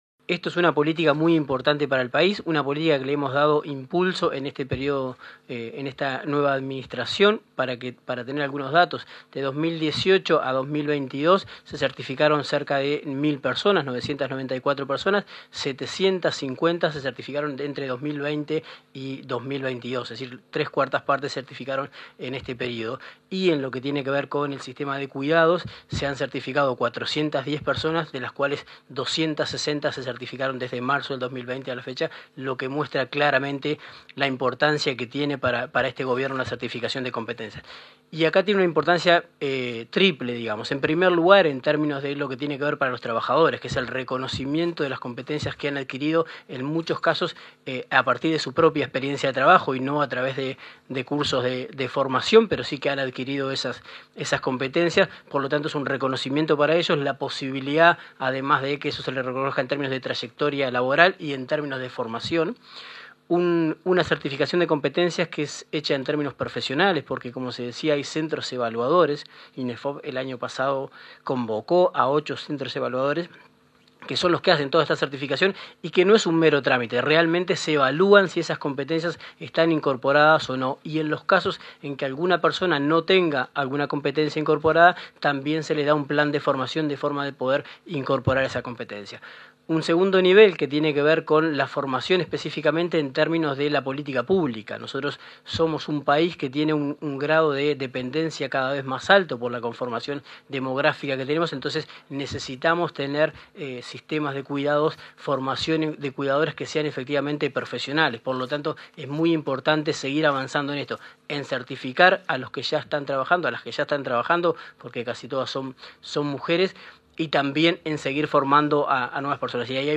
Conferencia de prensa por entrega de certificados de competencias en cuidados
Este 19 de agosto se realizó la entrega de certificaciones de competencias en cuidados y lanzamiento de llamado a certificación para centros de larga estadía, organizada por el Instituto Nacional de Empleo y Formación Profesional y la Dirección de Cuidados del Ministerio de Desarrollo Social. Participaron el director nacional de Empleo, Daniel Pérez; el director de Capacitación y Acreditación de Saberes de la Universidad del Trabajo del Uruguay, Jorge Musso, y la directora de Cuidados, Florencia Krall.